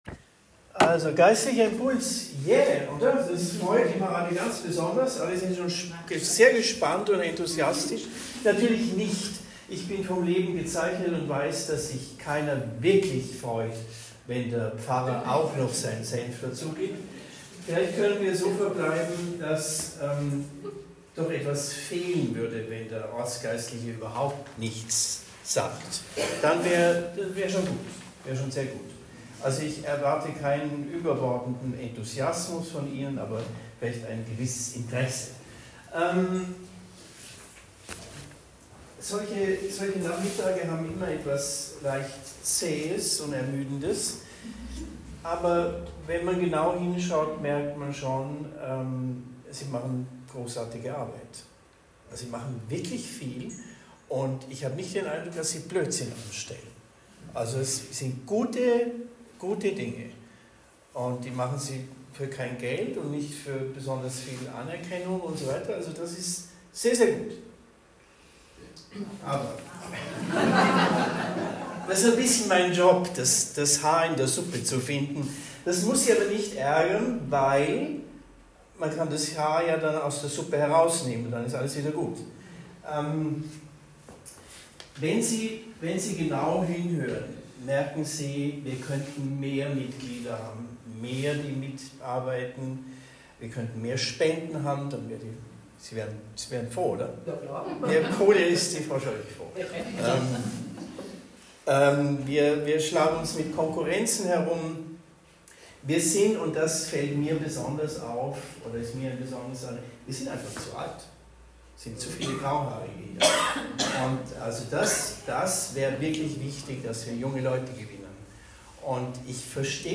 Geistlicher Impuls bei der Ortsversammlung der Malteser Wertheim
Predigt in Wertheim am 21. November 2025